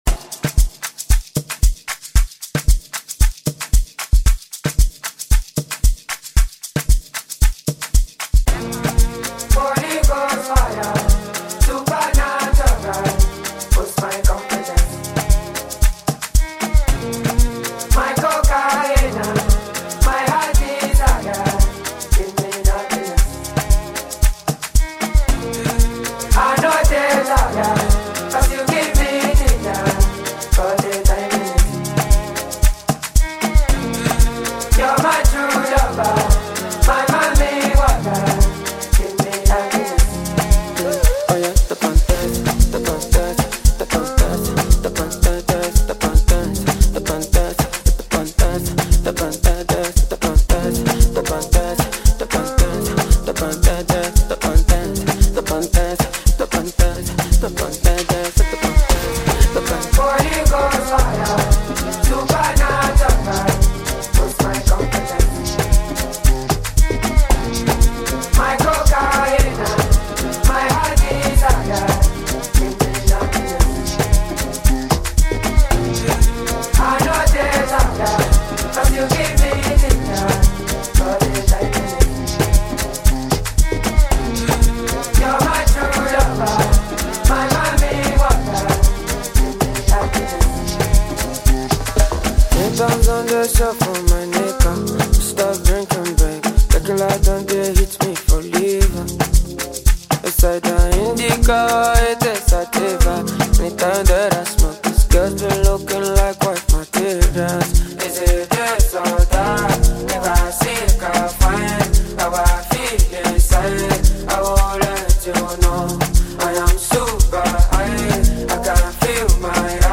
African Music